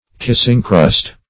Search Result for " kissingcrust" : The Collaborative International Dictionary of English v.0.48: Kissingcrust \Kiss"ing*crust`\, n. (Cookery) The portion of the upper crust of a loaf which has touched another loaf in baking.